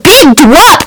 combobreakLOUD.wav